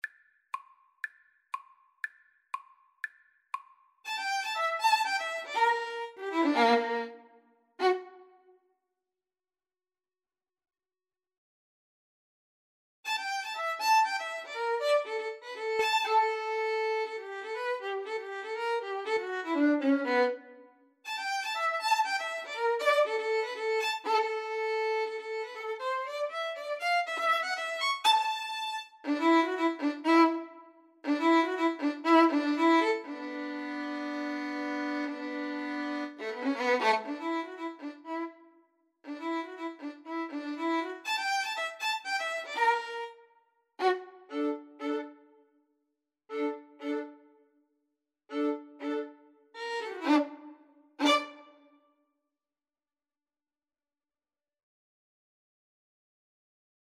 Allegro giusto (View more music marked Allegro)
Classical (View more Classical Violin-Cello Duet Music)